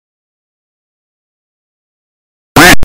canard-sound-effect.mp3